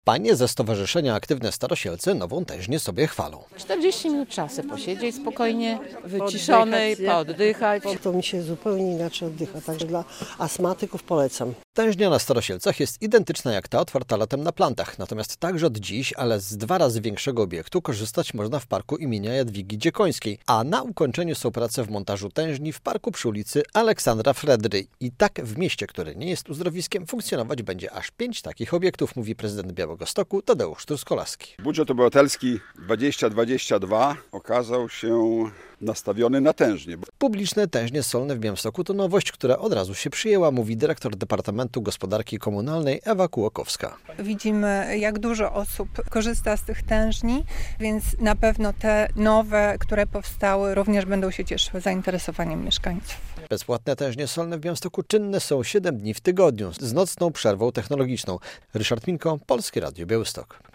To efekt głosowań mieszkańców miasta w budżecie obywatelskim w latach 2020-2022, który okazał się budżetem nastawionym na tężnie -  mówi prezydent Białegostoku Tadeusz Truskolaski.